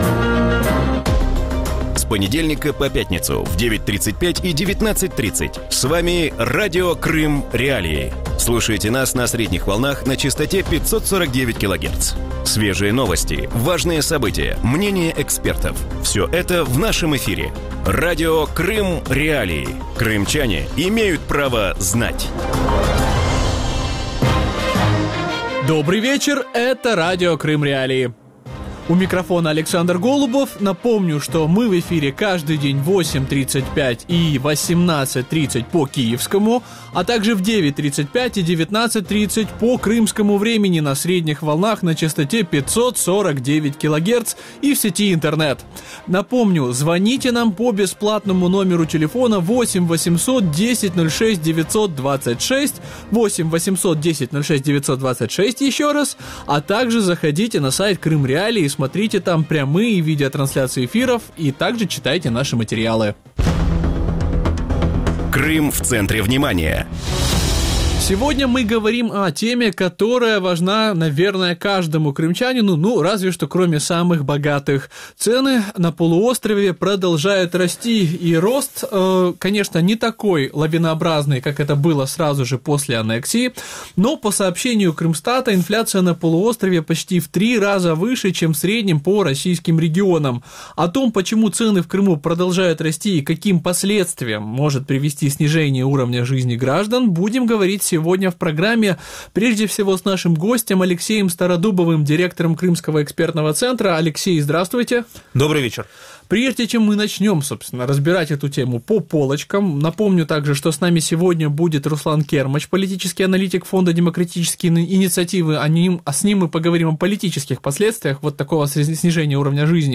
В вечернем эфире Радио Крым.Реалии говорят о росте инфляции в Крыму. Почему на аннексированном полуострове цены повышаются быстрее общероссийских и может ли снижение уровня жизни трансформироваться в протестные настроения?